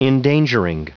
Prononciation du mot endangering en anglais (fichier audio)
Prononciation du mot : endangering